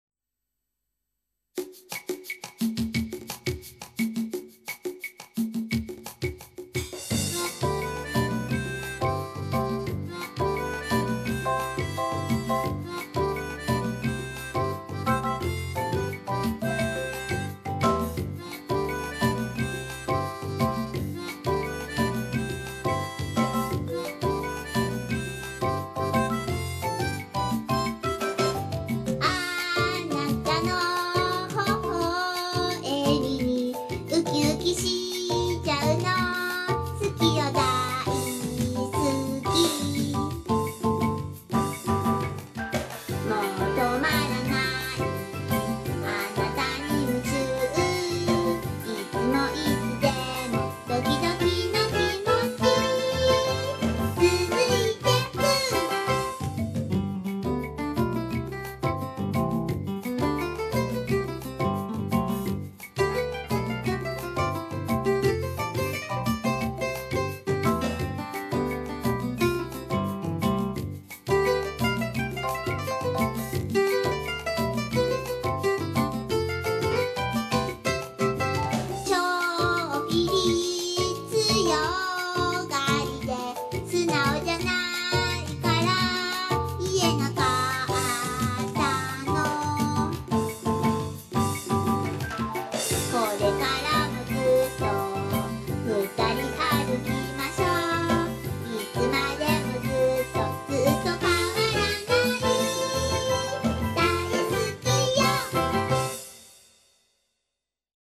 絶妙の歌唱力
本気の歌声なのか？それとも演技なのか？
本当に5歳児が歌っているかのような歌唱に感激しております。
音量は意図的に小さめにしてあります。